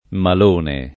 [ mal 1 ne ]